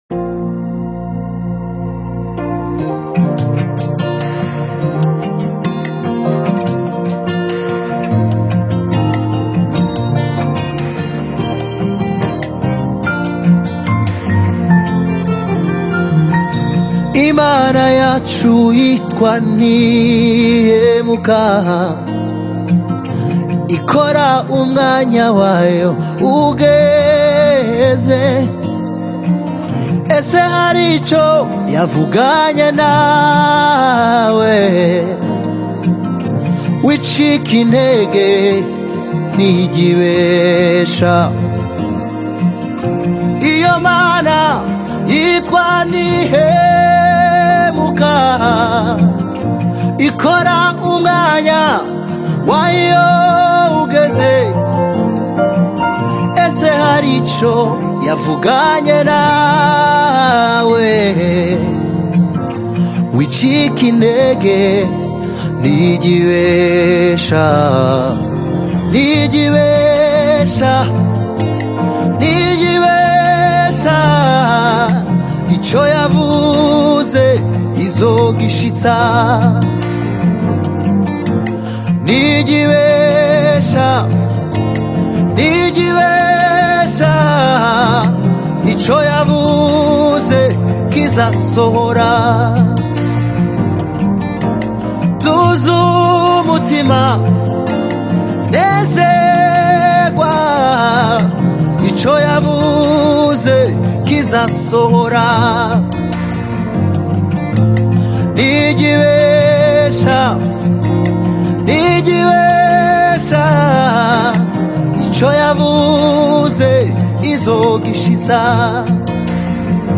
inspirational song
featuring talented vocalist
Through heartfelt lyrics and a soulful melody